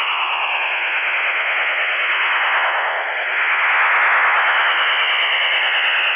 сигнал с замираниями
stanag_4285.wav